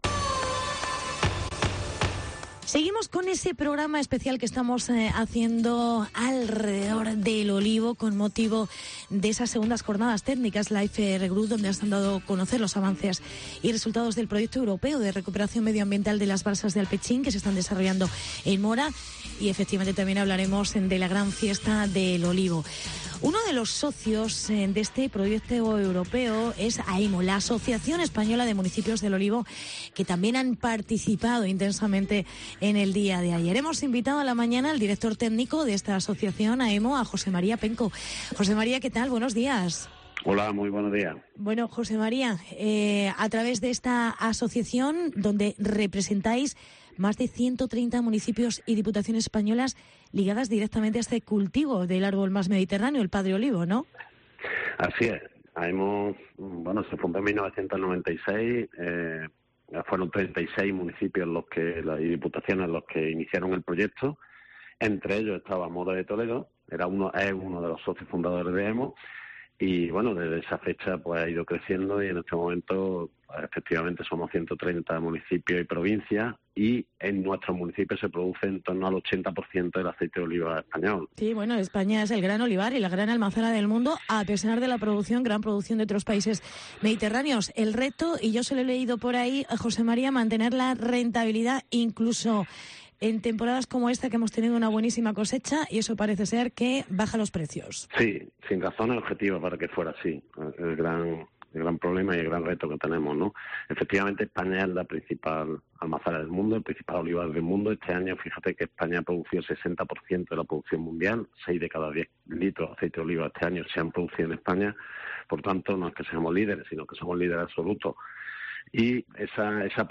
España líder absoluto en aceite de oliva con el 60% de la producción mundial. Entrevista